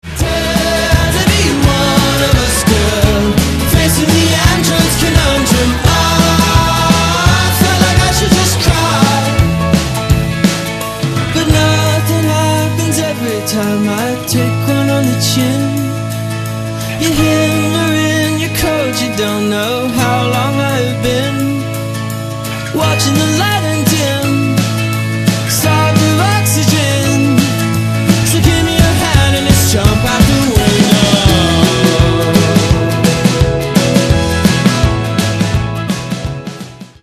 one of the brighter tracks on the disc